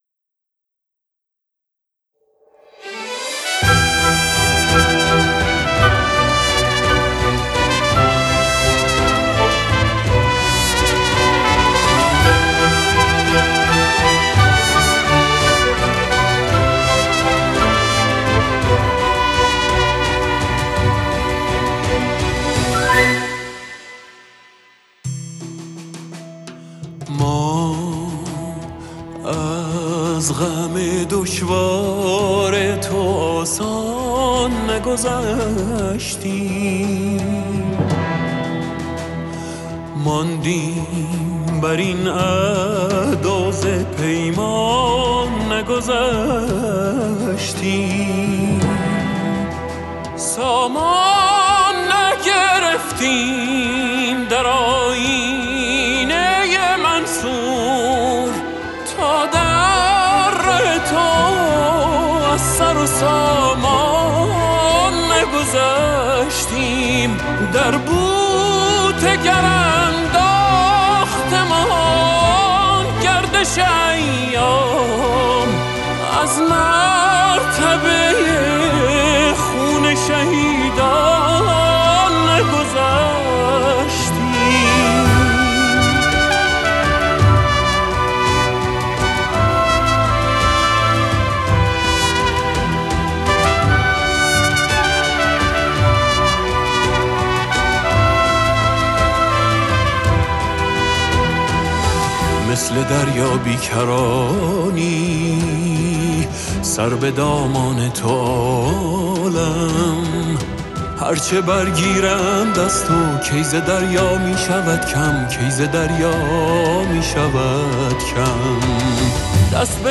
ویولن و ویولا
ویولنسل
ترومپت و فلوگل
فلوت
سازهای کوبه‌ای